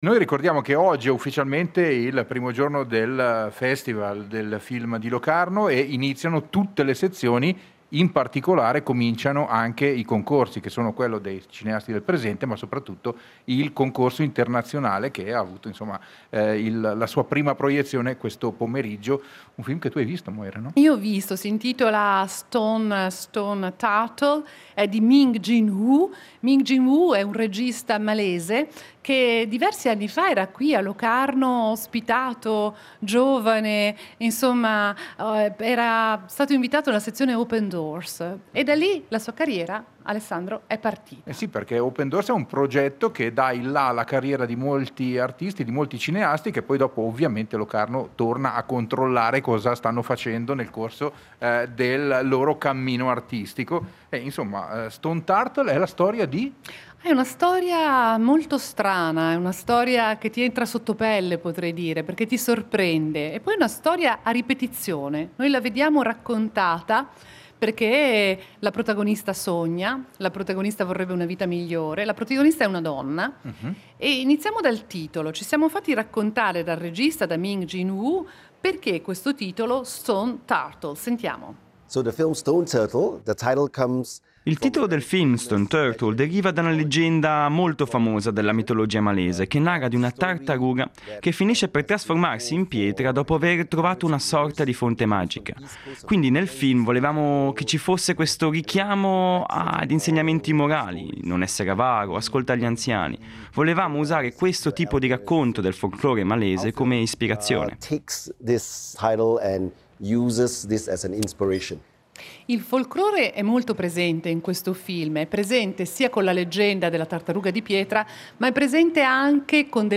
in diretta dalla 75esima edizione del Locarno Film Festival